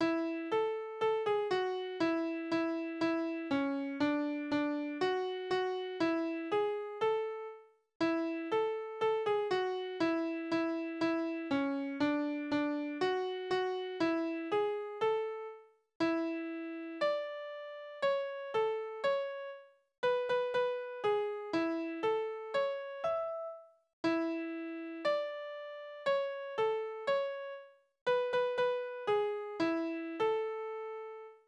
Balladen: Die 5 wilden Schwäne
Tonart: A-Dur
Taktart: 4/4
Tonumfang: kleine Dezime